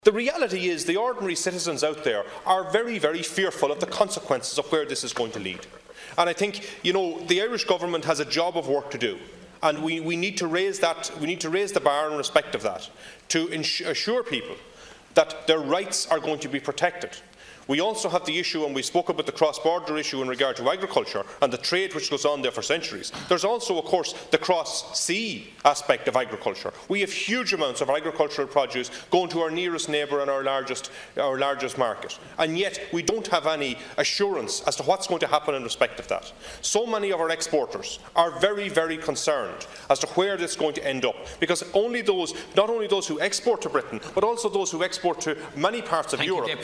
Following the meeting yesterday, Sligo, Leitrim and South Donegal Deputy Martin Kenny told the Dail that the Irish Government has a duty to reassure people in the face of Brexit: